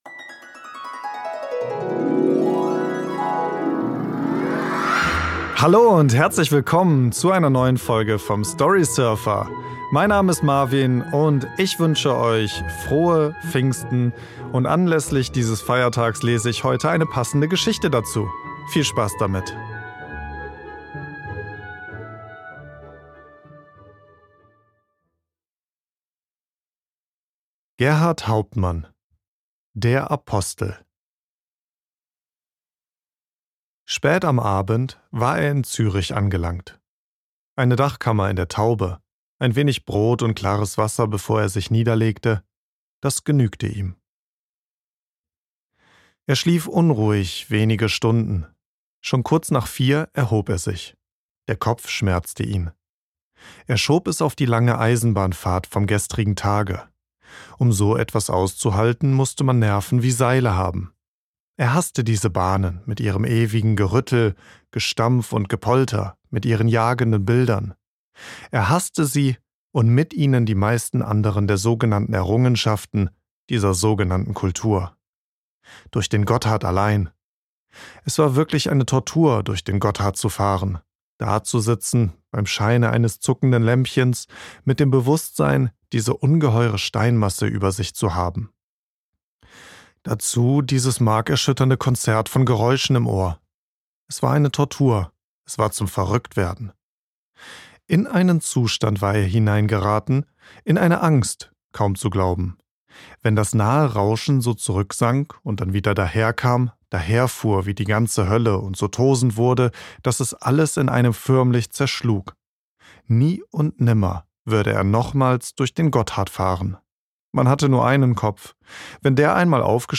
Gerhart Hauptmann - Der Apostel | Pfingstgeschichte über Wahn und Frieden | Storysurfer Podcast ~ Storysurfer - Der Kurzgeschichten Hörbuch Podcast